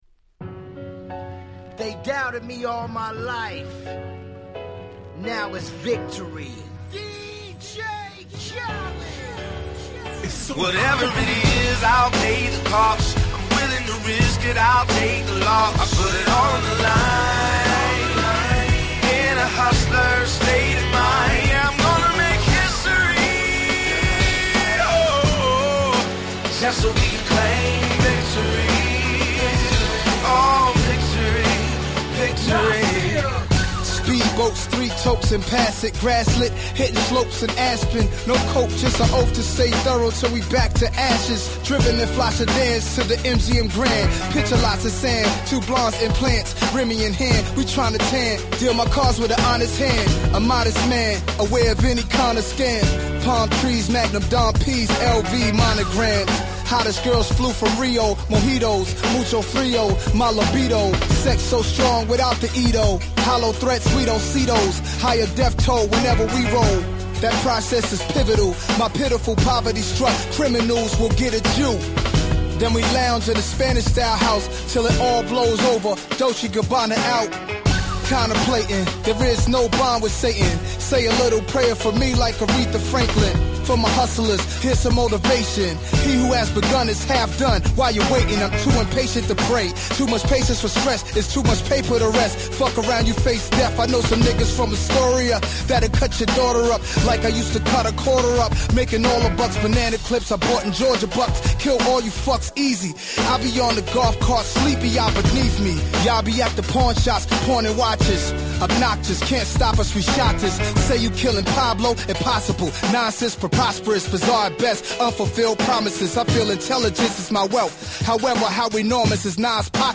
10' Super Hit Hip Hop !!